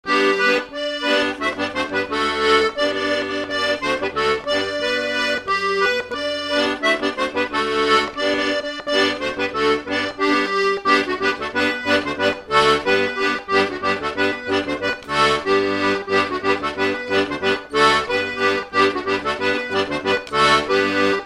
Localisation Saint-Paul-Mont-Penit
Fonction d'après l'analyste danse : scottish (autres)
Catégorie Pièce musicale inédite